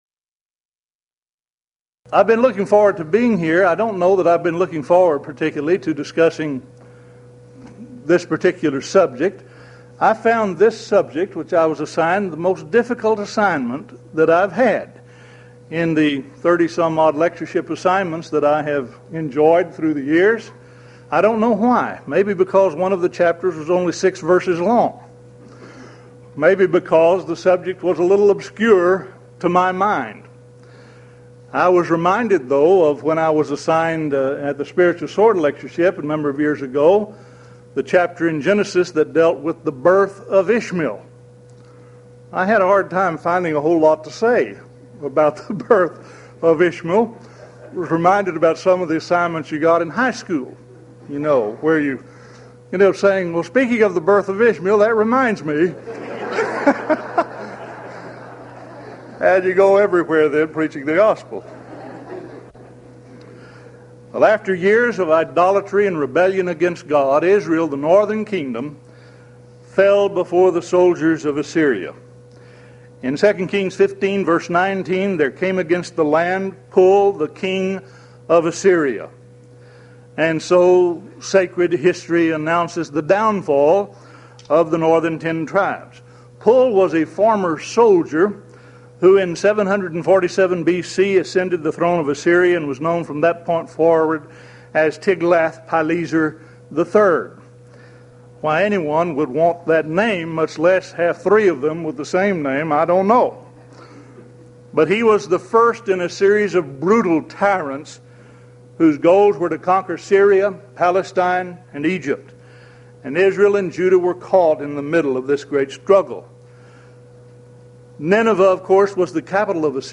Series: Houston College of the Bible Lectures Event: 1995 HCB Lectures Theme/Title: The Book Of Isaiah - Part I